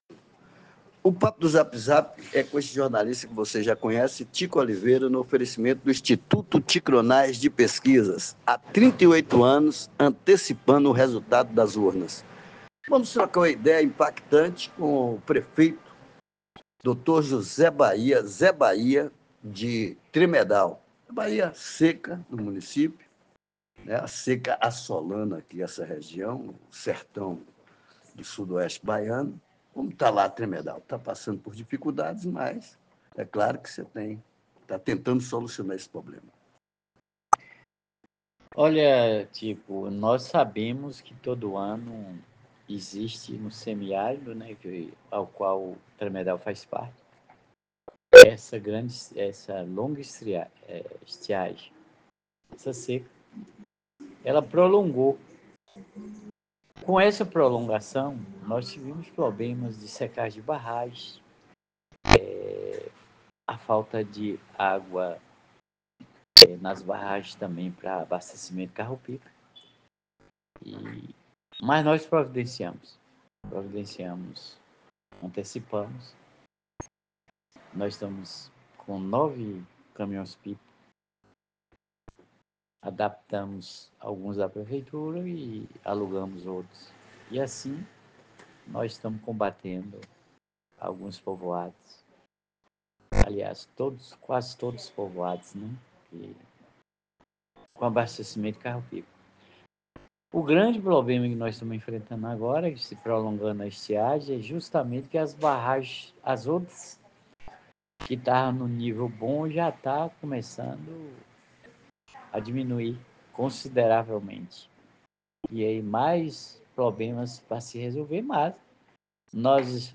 No Papo do Zap Zap, o prefeito Dr. Zé Bahia fala sobre o programa implementado com recursos próprios da Prefeitura Municipal de Tremedal para mitigar os problemas causados pela estiagem.